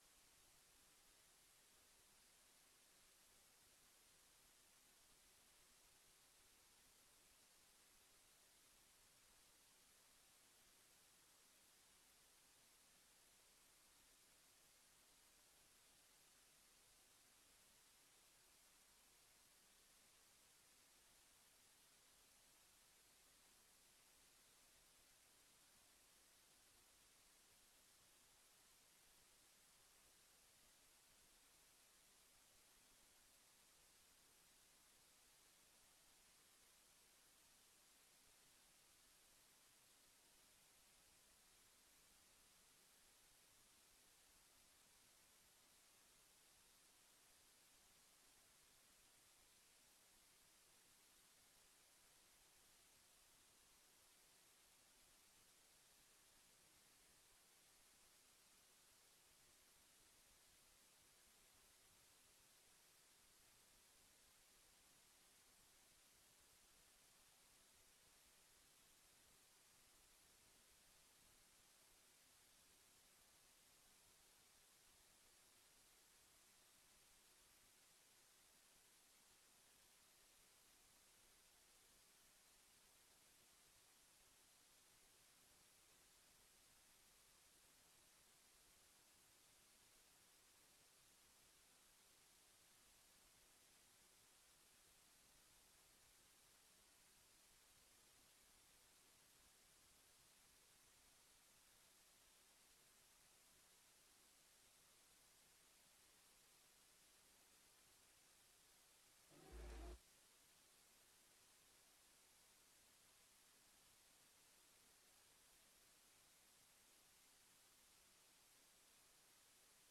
Bijzondere raadsvergadering ter afscheid van Koen Schuiling als burgemeester van Groningen.